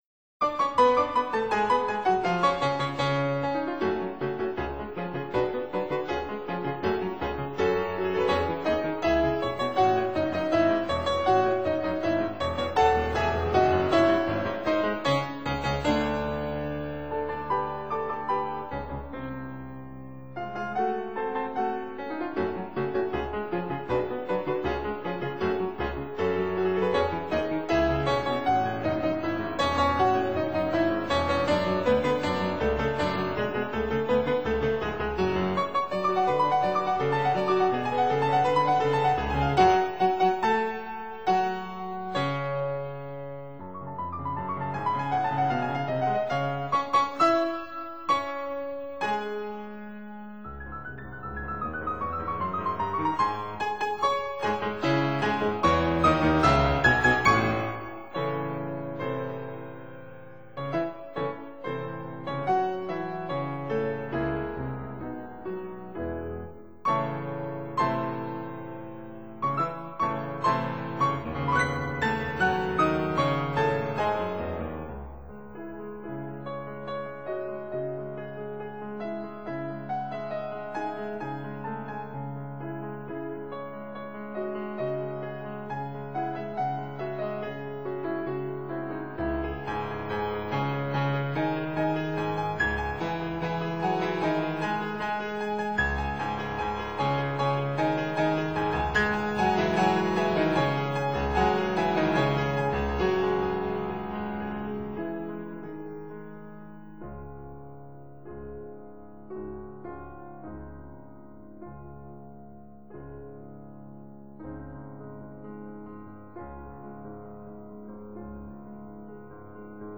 其作品為浪漫主義風格，受勃拉姆斯影響較大。